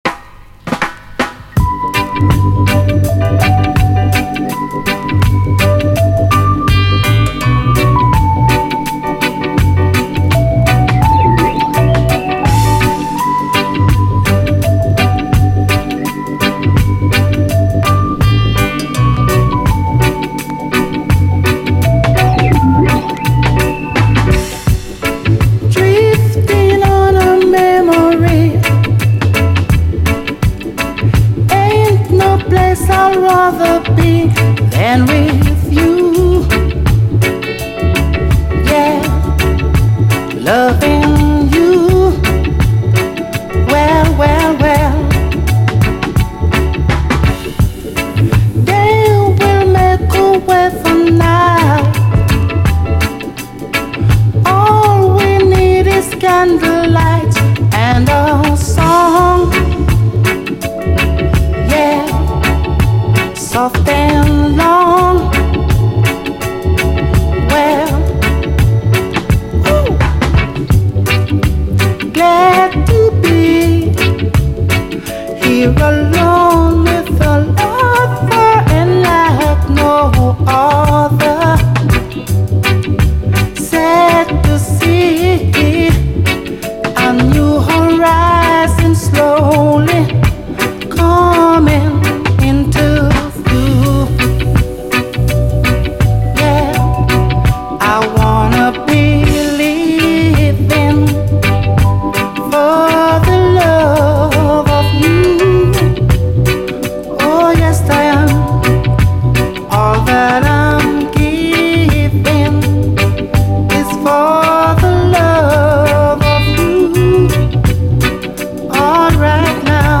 REGGAE
UKラヴァーズ・カヴァー！まろやかなオルガンが優しいグレイト・ヴァージョン！